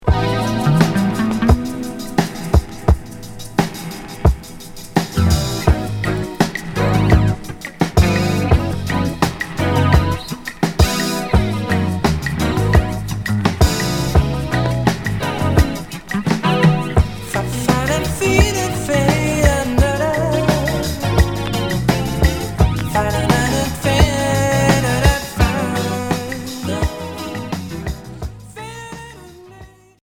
Progressif Unique 45t retour à l'accueil